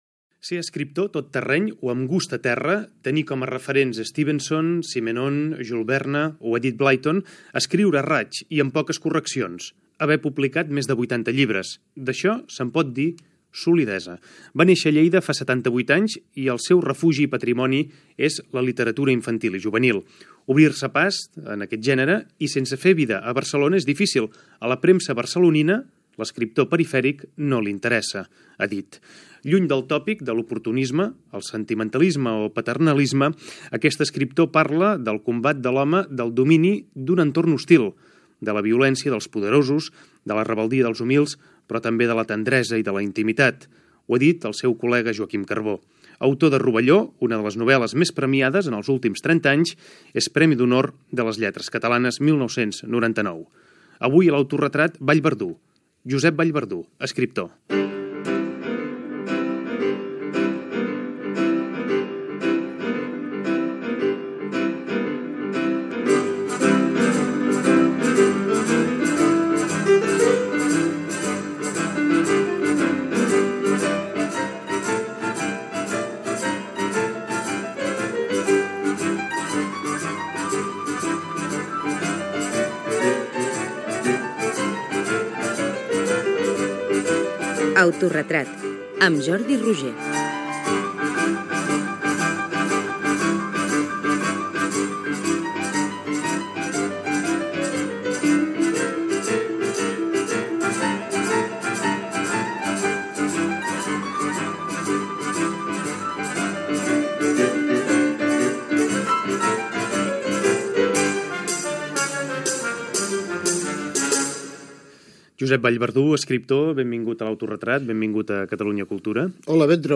careta del programa, entrevista a l'escriptor sobre la seva trajectòria